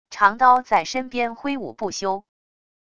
长刀在身边挥舞不休wav音频